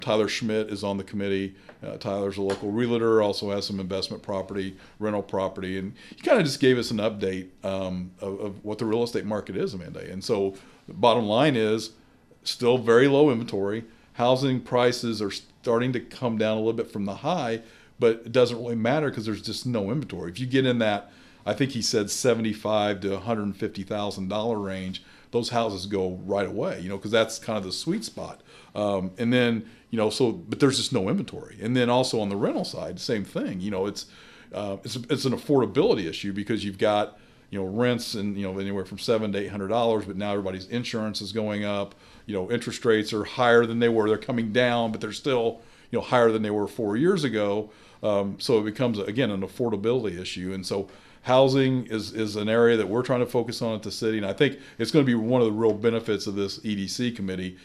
Speaking on our podcast, “Talking about Vandalia,” Mayor Knebel says one area they got a bit of an update on was the tight housing market in the community.